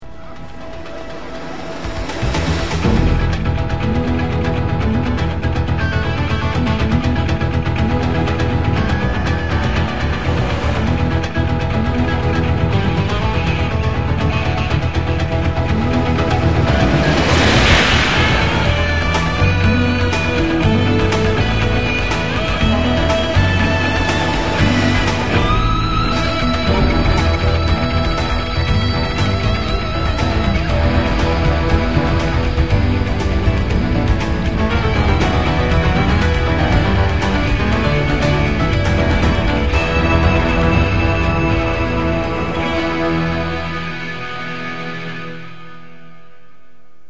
I hope the join isn't too noticeable
theme music